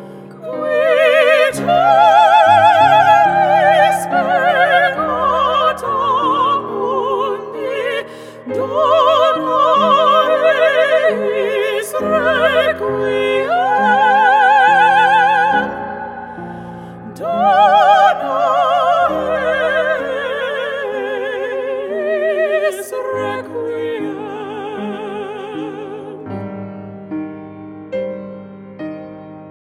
Inspirational With A Classical Twist.